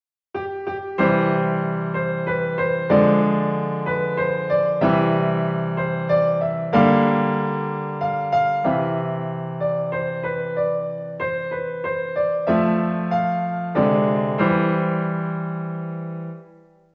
Pero es cuestión de que escuches y decidas en que acordes queda bien y en cuales no. A continuación, escucharás “Las Mañanitas” con una combinación de armonía negativa y positiva.